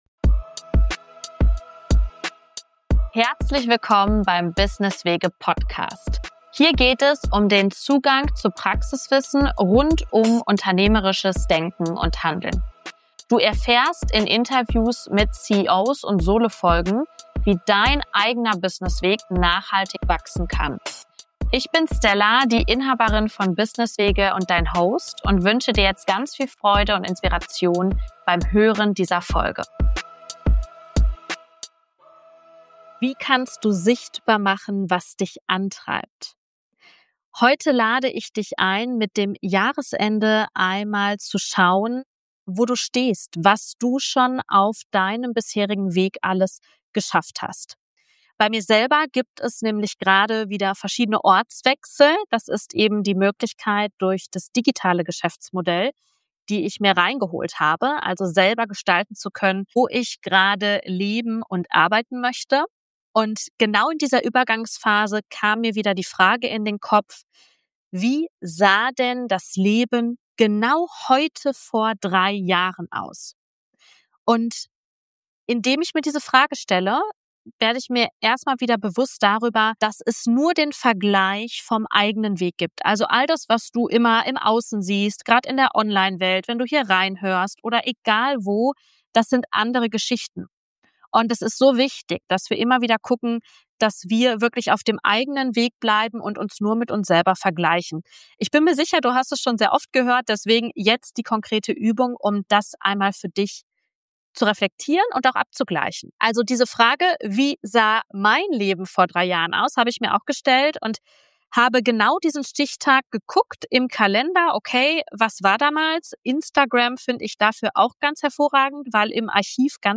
Beschreibung vor 4 Monaten In dieser Solo-Folge geht es um eine der wichtigsten Fragen für deinen unternehmerischen Weg: Wie wirst du dir bewusst, was dich antreibt und wie machst du es sichtbar?Mit dem Jahresende lade ich dich ein innezuhalten, deinen Weg der letzten Jahre zu reflektieren und deine Vision für die kommenden Schritte bewusst zu gestalten.